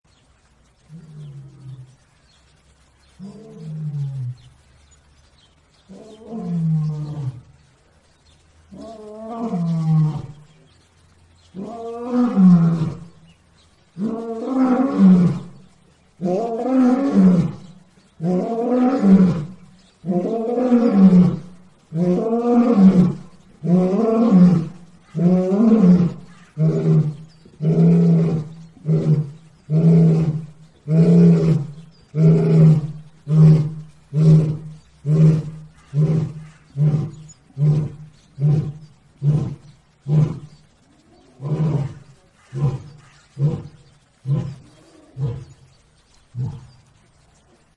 Lion Loud Téléchargement d'Effet Sonore
Lion Loud Bouton sonore